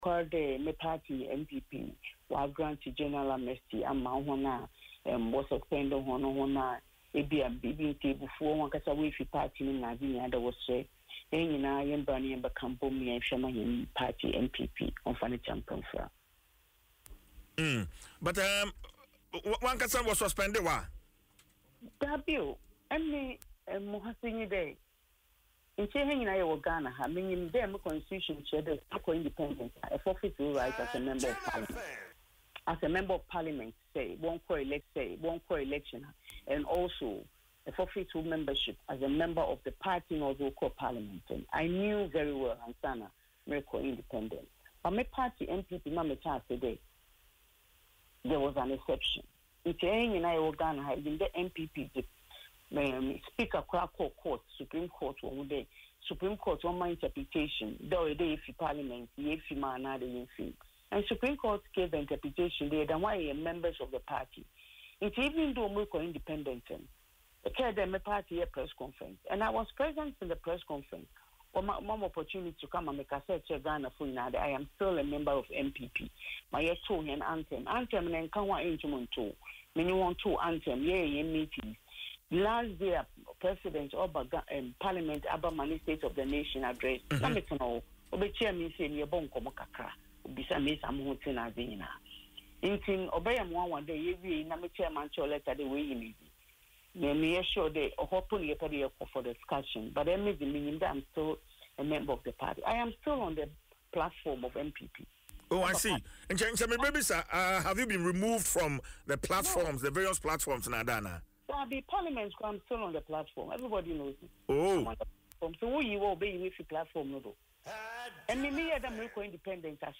Speaking in an interview on Adom FM’s Dwaso Nsem, Mrs. Morrison clarified that although she once contested as an independent candidate, she was never formally suspended by the NPP.